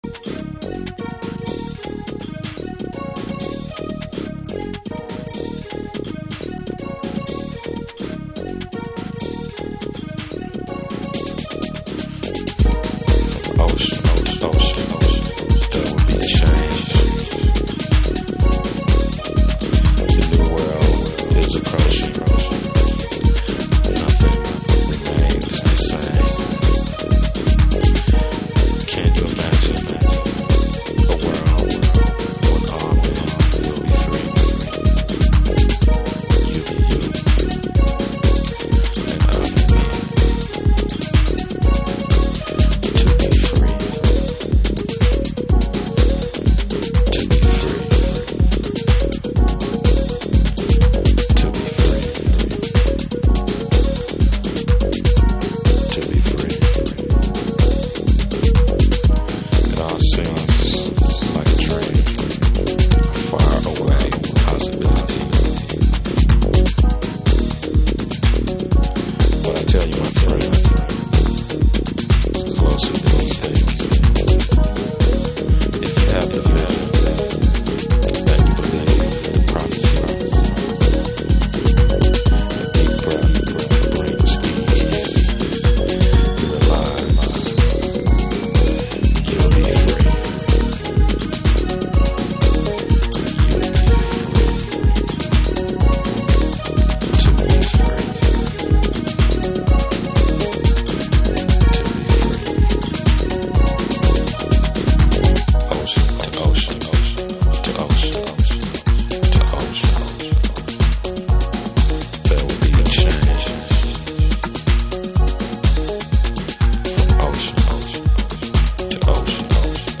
Detroit House / Techno